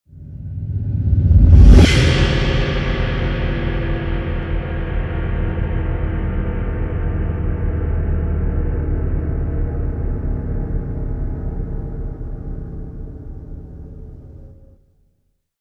creepy-sound